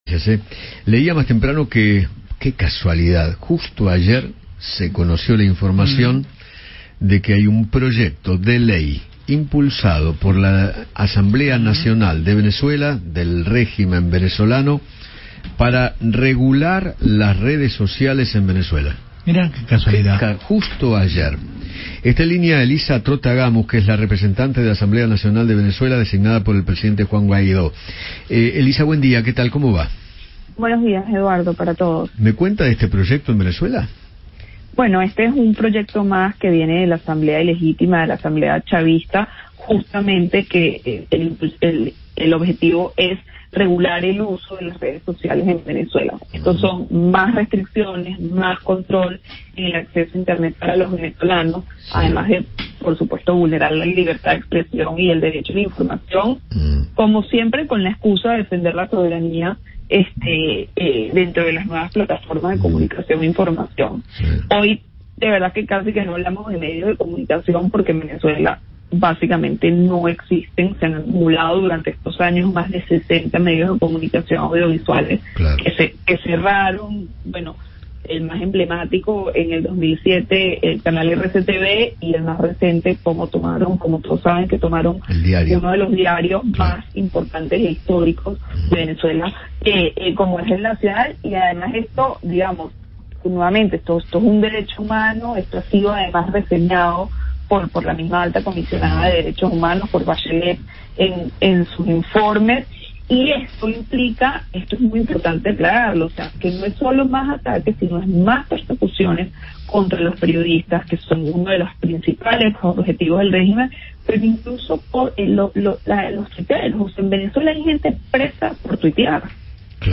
Elisa Trotta Gamus, representante de la Asamblea Nacional de Venezuela en Argentina, conversó con Eduardo Feinmann sobre el proyecto que anunció el Gobierno para promover “el buen uso de las redes sociales”.